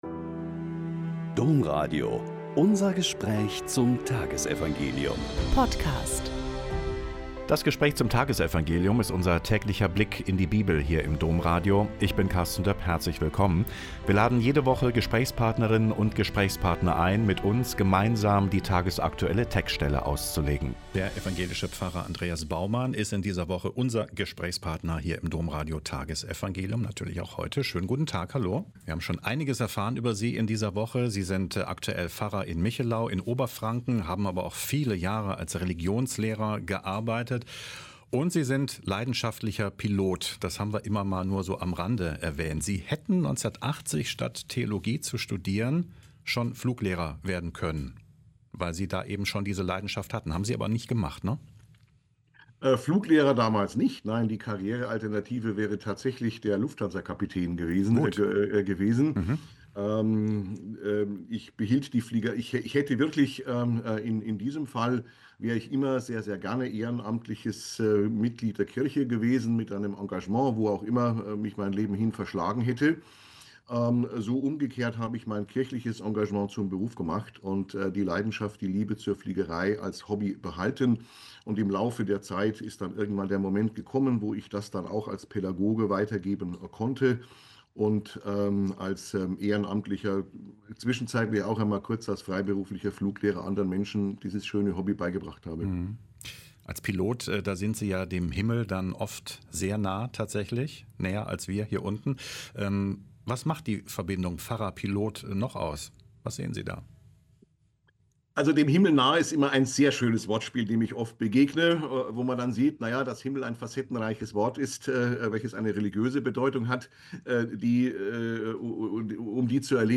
Lk 11,5-13 - Gespräch